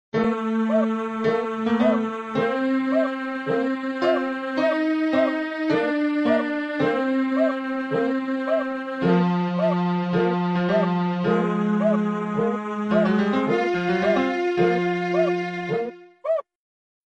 Самый громкий самолет в истории sound effects free download
Самый громкий самолет в истории - это американский экспериментальный истребитель Republic XF-84H Thunderscreech. Он получил прозвище "Вопящий гром" из-за чрезвычайно высокого уровня шума, создаваемого его винтом, который вращался на околозвуковой скорости.